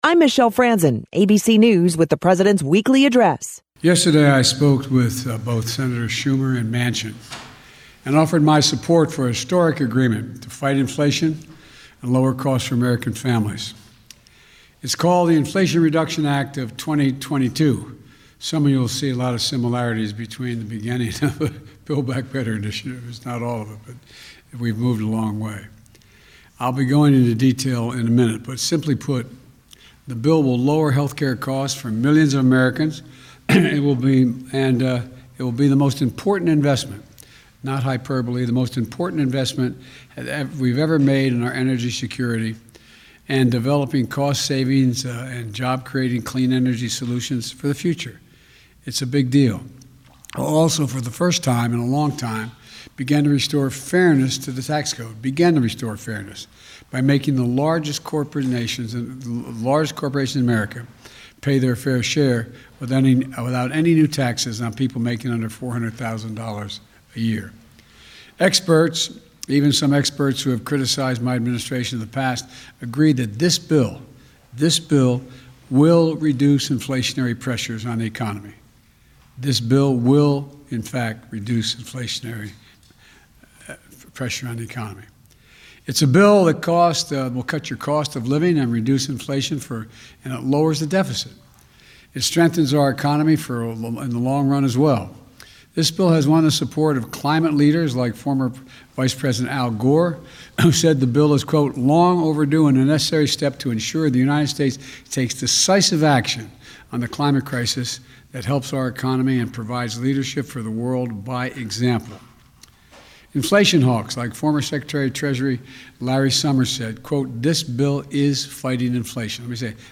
President Biden spoke about the Inflation Reduction Act of 2022.